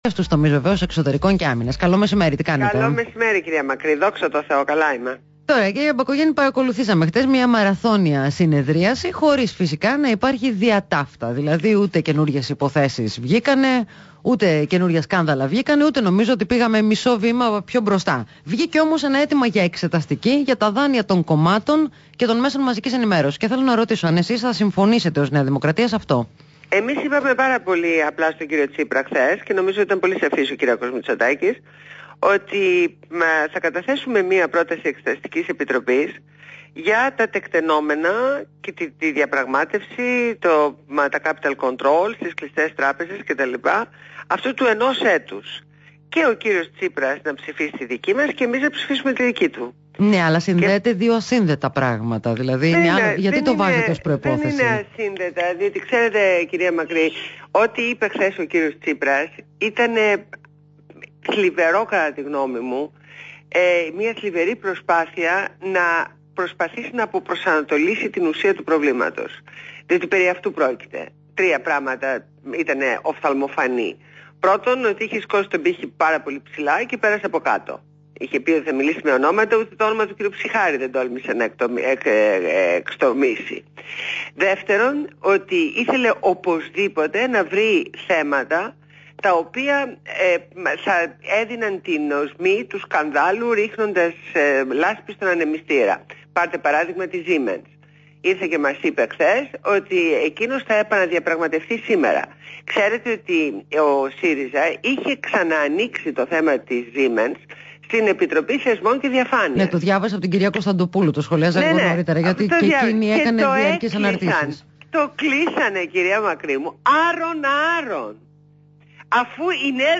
Συνέντευξη στο ραδιόφωνο REAL fm